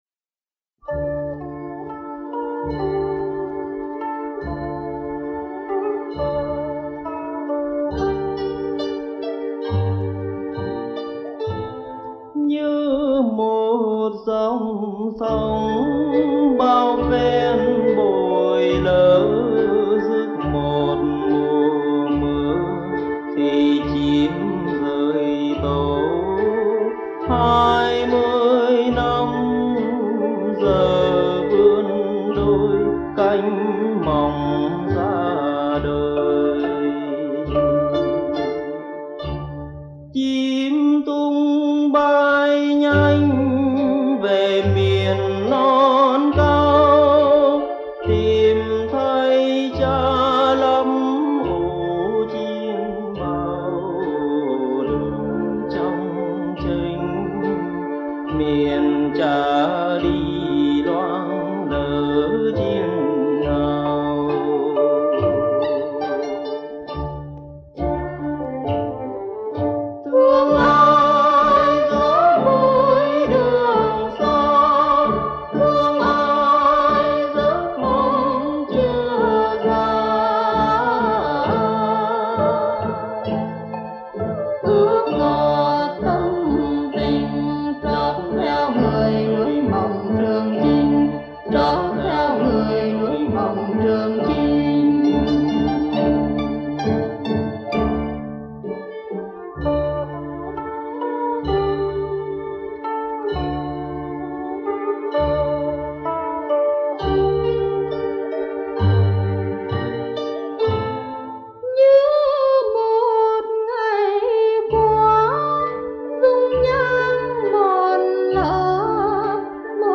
Ban nhạc